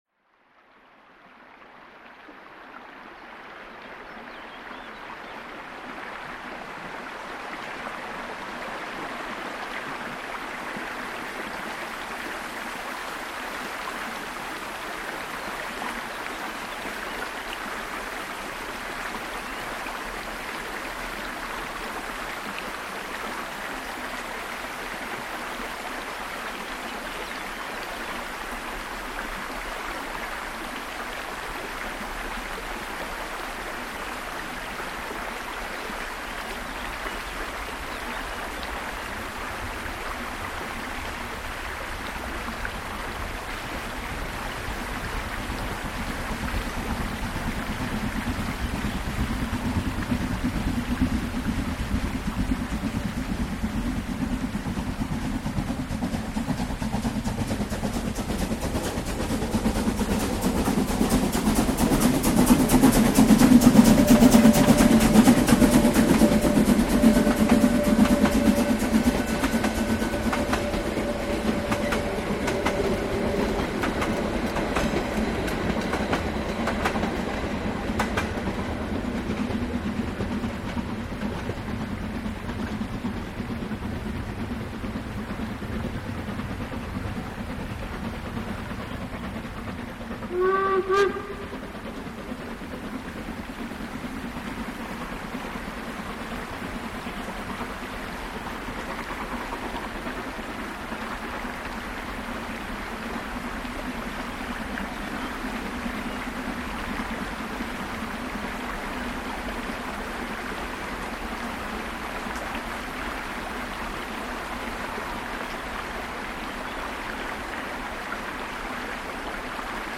Soundaufnahmen von Dampflokomotiven, nur hochwertige, nur vollständige und alle zum Downloaden in guter (stereo)-Qualität